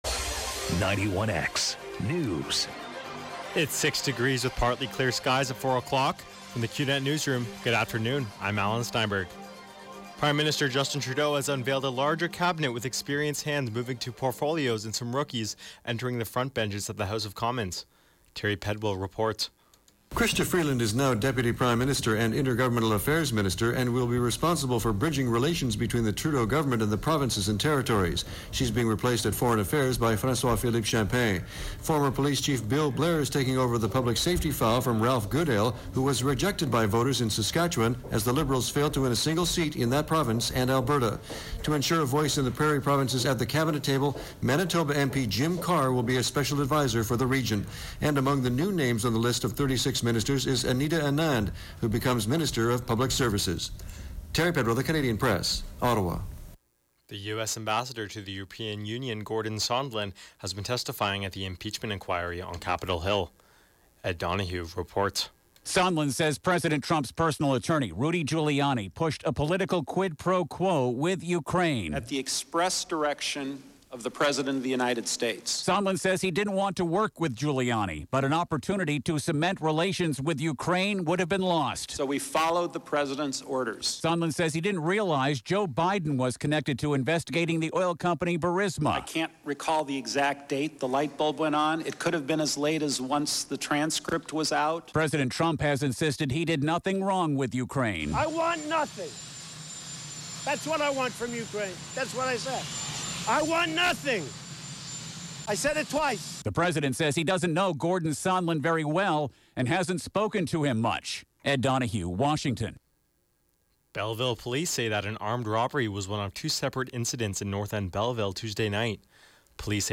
91X Newscast: Wednesday Nov. 20, 2019, 4 p.m.